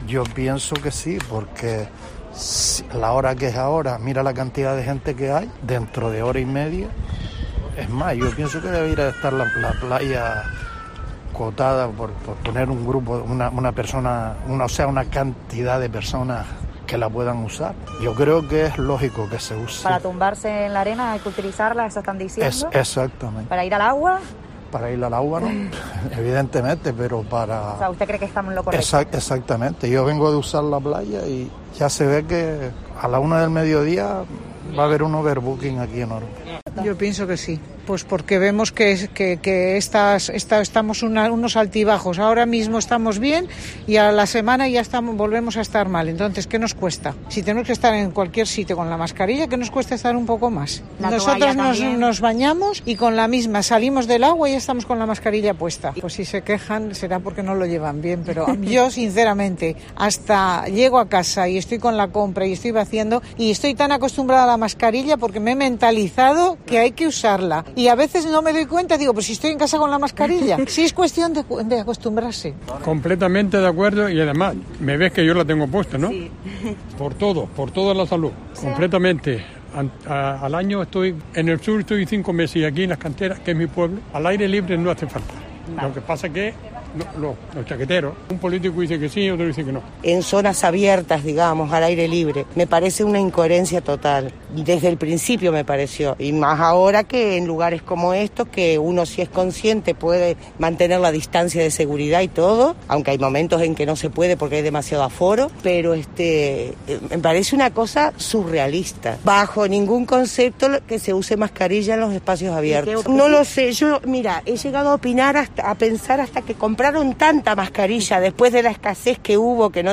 Varios usuarios de la playa de Las Canteras opinan sobre la obligatoriedad del uso de mascarillas